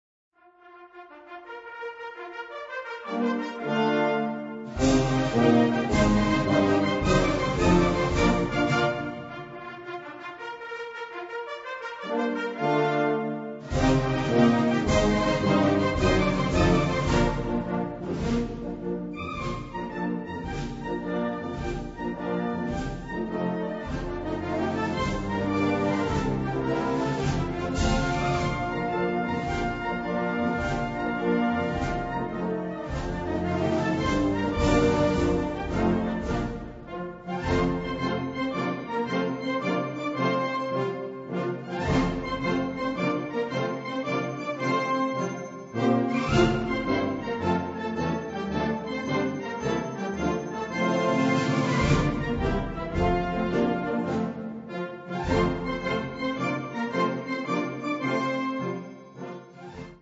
Gattung: Marsch
A4 Besetzung: Blasorchester PDF